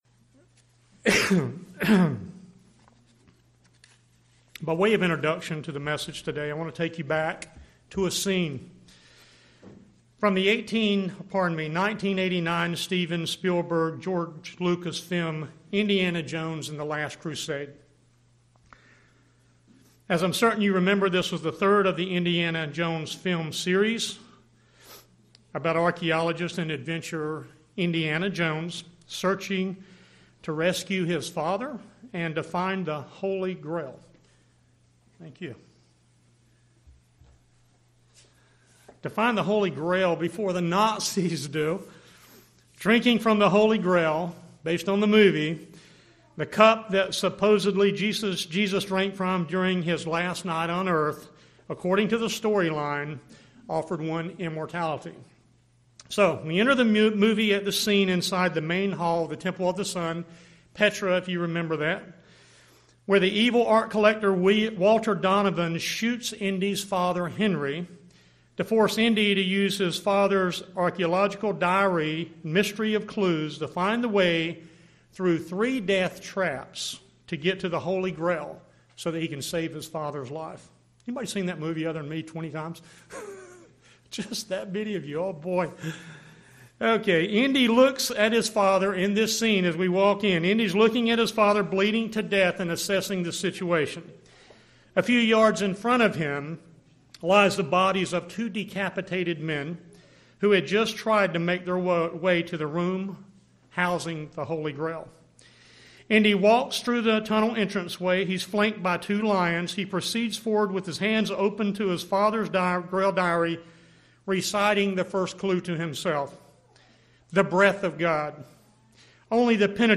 Given in Raleigh, NC